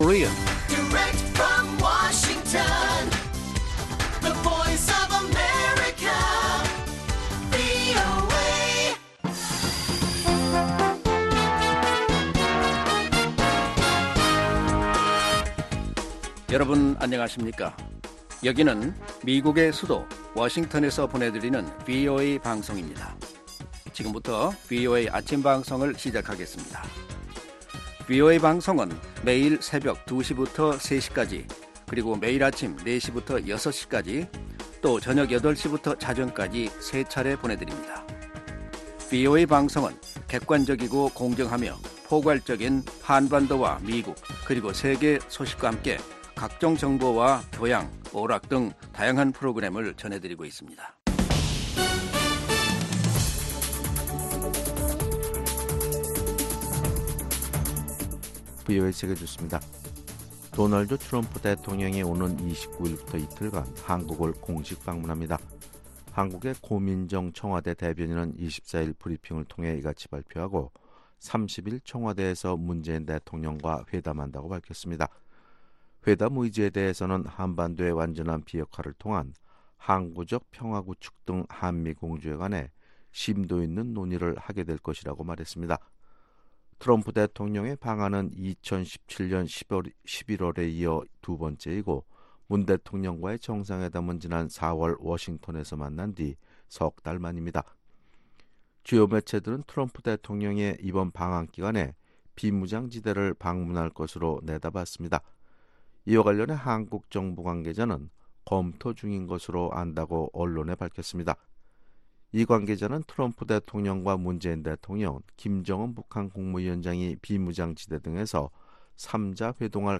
세계 뉴스와 함께 미국의 모든 것을 소개하는 '생방송 여기는 워싱턴입니다', 2019년 6월 25일 아침 방송입니다. ‘지구촌 오늘’은 이란의 미군 무인기 격추 사태로 미국과 이란 간 긴장이 고조되는 가운데 미국 정부가 24일, 이란에 대한 새로운 제재를 부과한다는 소식, ‘아메리카 나우’에서는 도널드 트럼프 대통령이 대규모 불법체류자 추방 작전을 2주 연기한다고 밝혔다는 이야기를 소개합니다. '구석구석 미국 이야기'에서는 몸과 마음의 문을 여는 음악치료 이야기를 소개합니다.